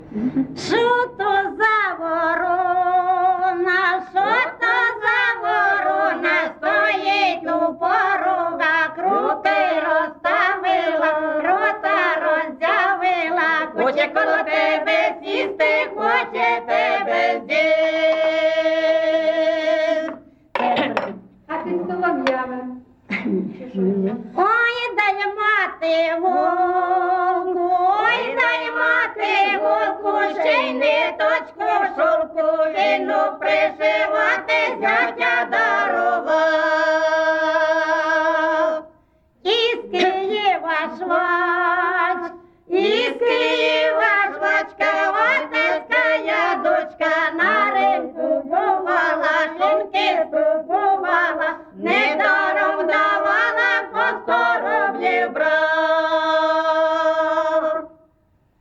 ЖанрВесільні
Місце записус. Писарівка, Золочівський район, Харківська обл., Україна, Слобожанщина